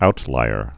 (outlīər)